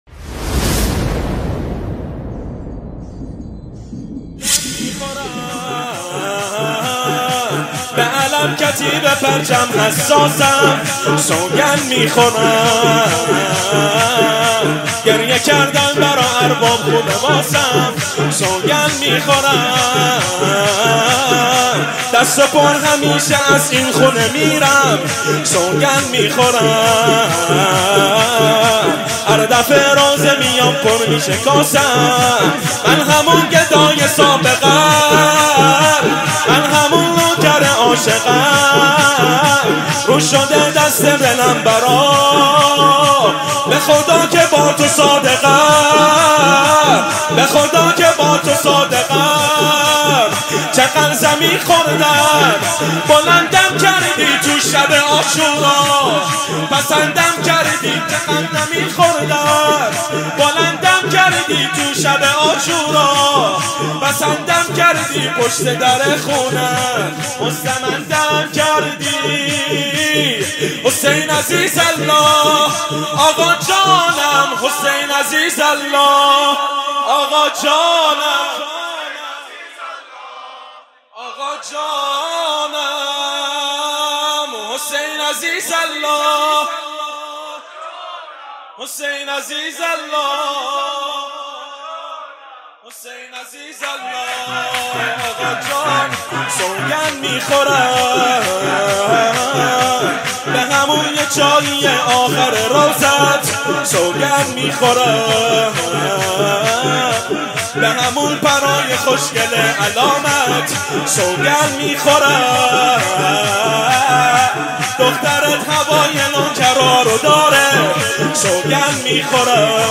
شور | سوگند میخورم دست پُر همیشه از این خونه میرم
مداحی
مراسم عزاداری شب دوم محرم 1440 هجری قمری